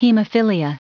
Prononciation du mot hemophilia en anglais (fichier audio)
Prononciation du mot : hemophilia